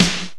EMX SNR 2.wav